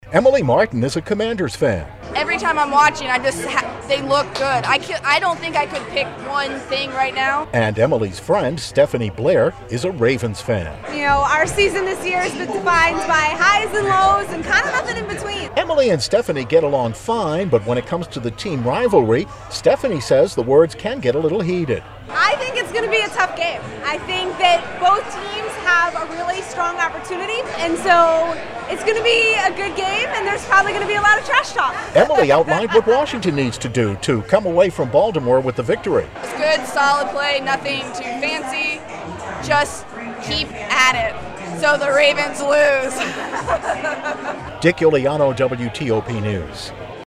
talks with fans on both sides about what each team needs to do to win.
1-wash-balt-fans-redo-dul.wav